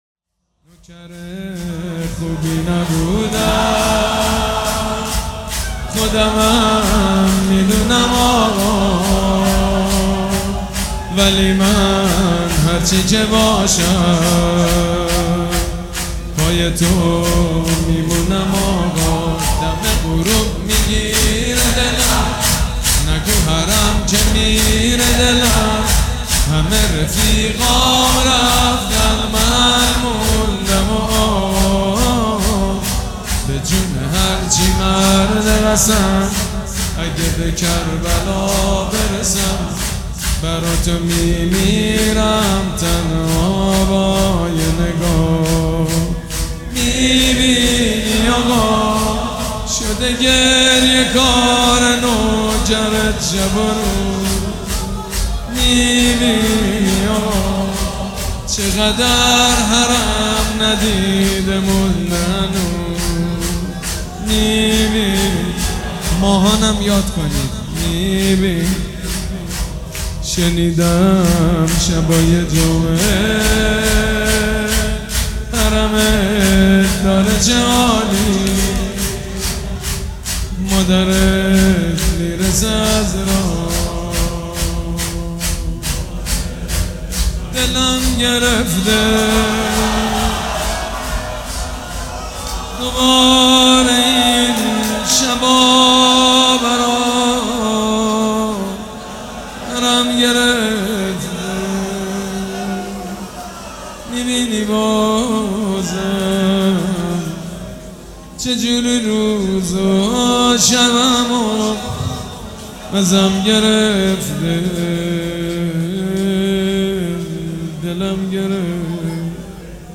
شور
مداح
مراسم عزاداری شب شام غریبان